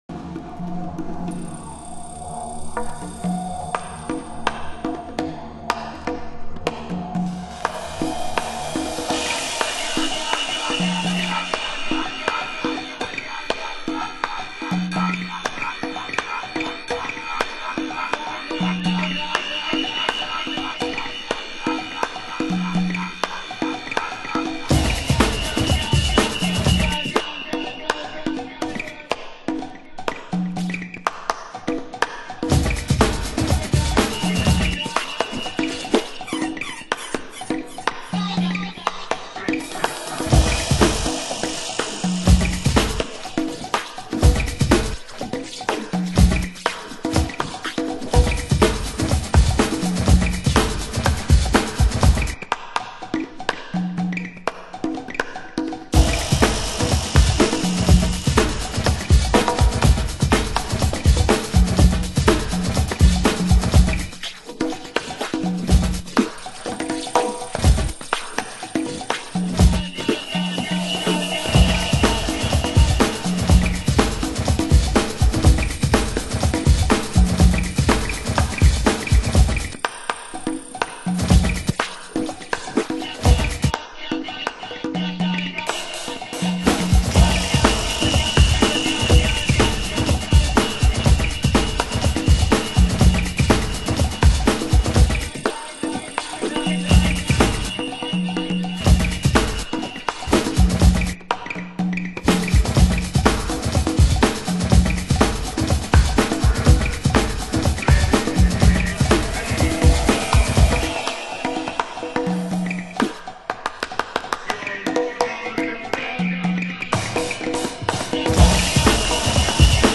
盤質：良好/盤面にプレス時の小さな凸有（試聴箇所になっています）